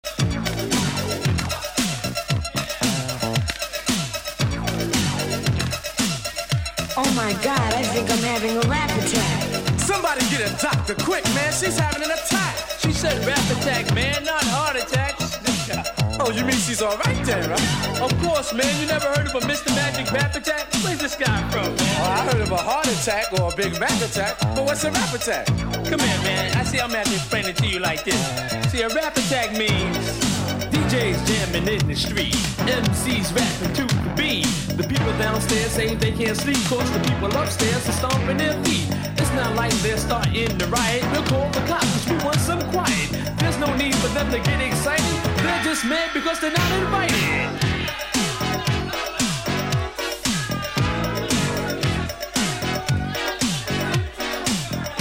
"Rap/Scratch"
la sonorité rap du début 80's